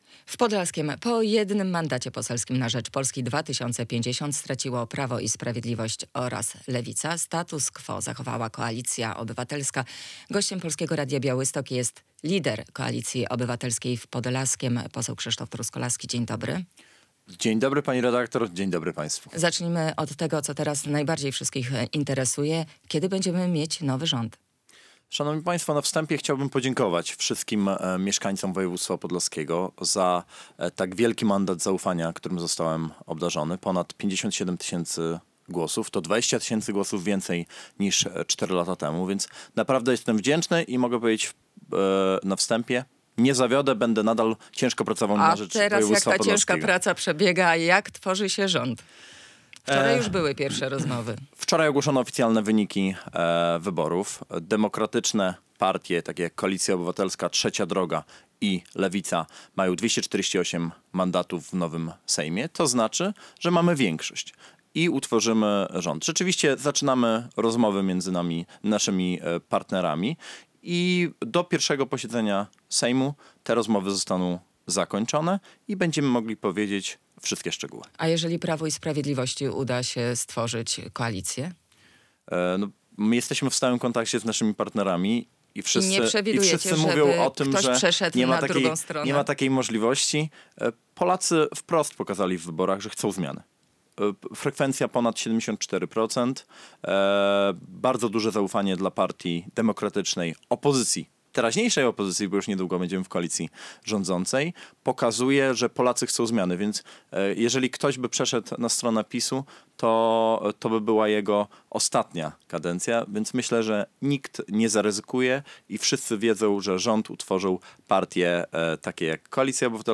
Radio Białystok | Gość | Krzysztof Truskolaski [wideo] - poseł, lider Koalicji Obywatelskiej w Podlaskiem